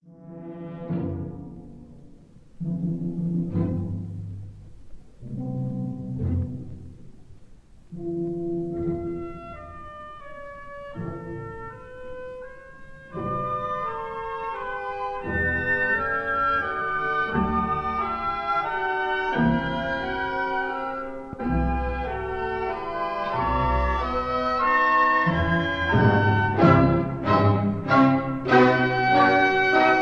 *live recording made October 1948